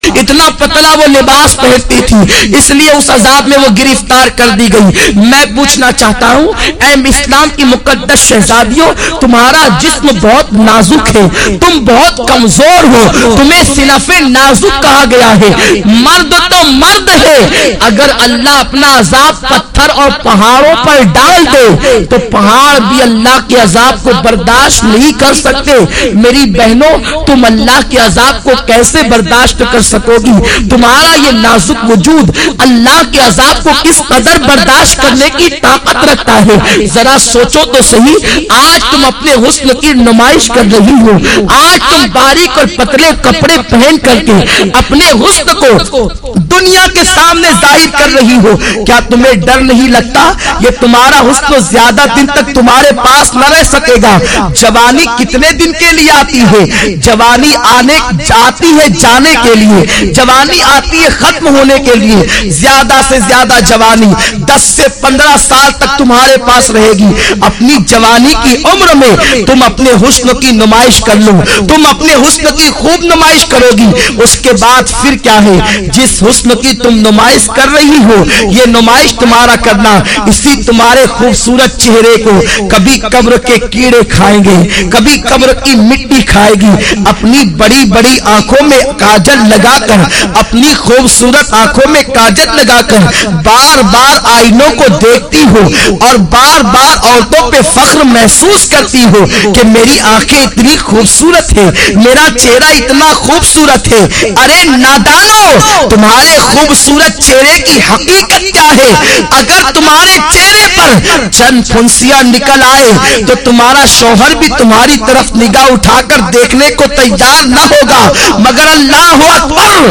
Beautiful Byan mp3